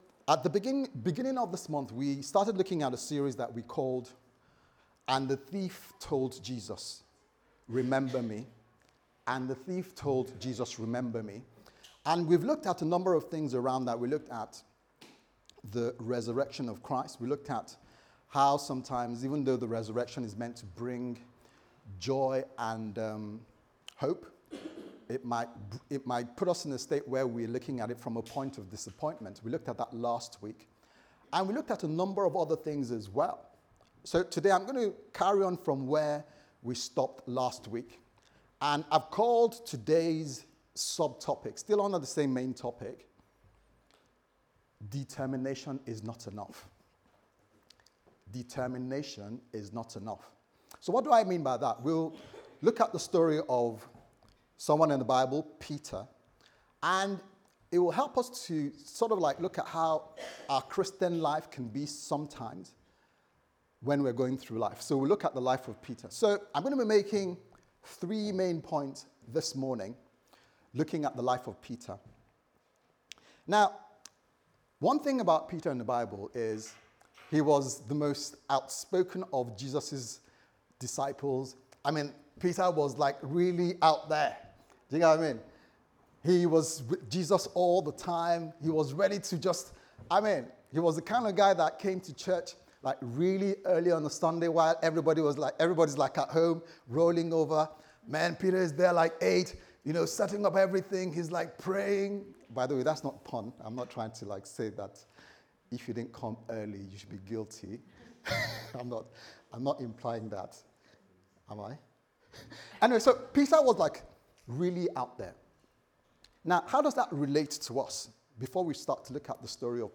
The Thief Said To Jesus Remember Me Service Type: Sunday Service Sermon « Real Life Sessions